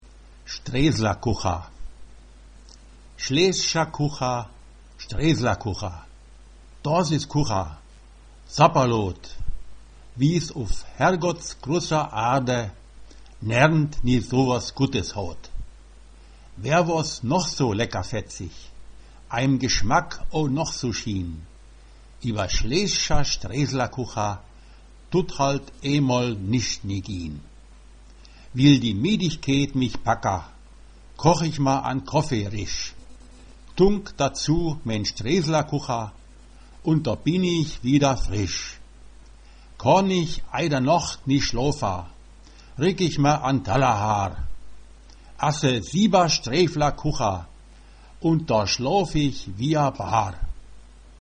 Schlesisch
- nach einem Mundartgedicht von Hermann Bauch -